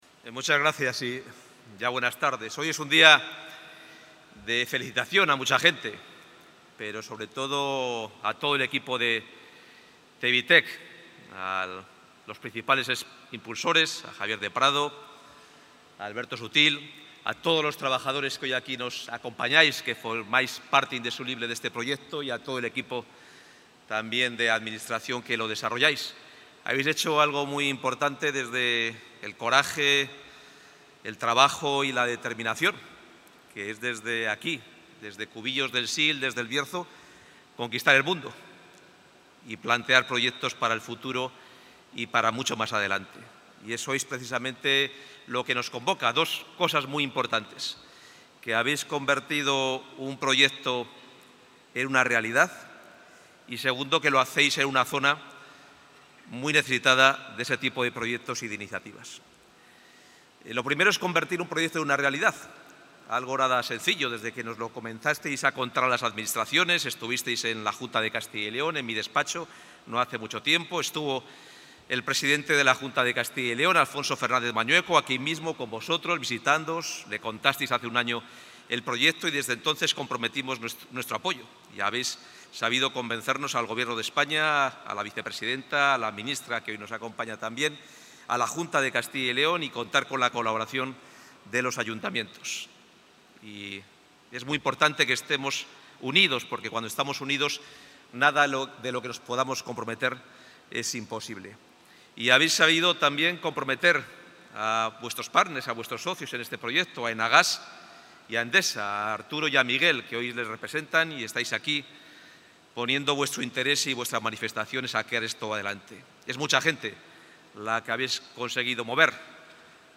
Intervención del consejero de Economía y Hacienda.